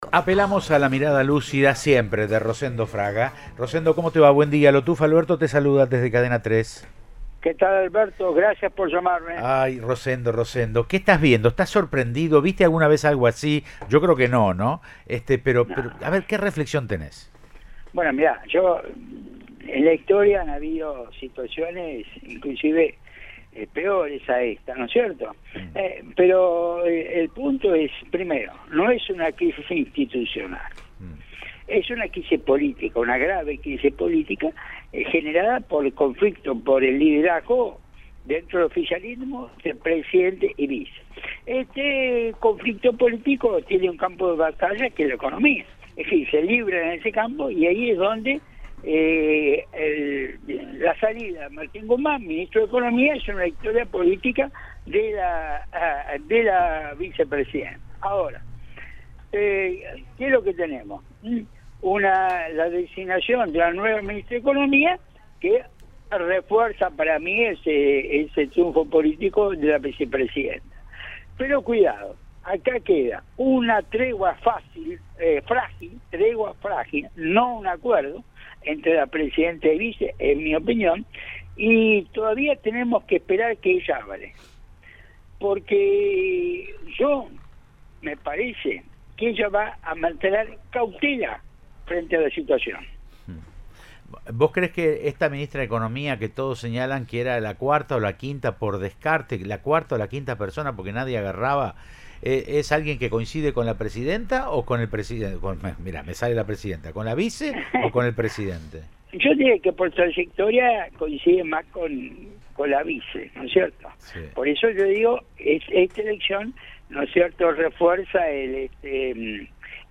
El periodista y analista político dialogó con Cadena 3 Rosario sobre el recambio en el Ministerio de Economía y calificó el hecho como "una victoria de Cristina Fernández de Kirchner".